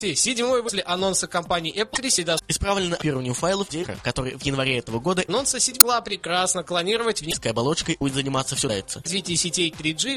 Жанр: новостной Apple-podcast
Битрейт аудио: 80-96, stereo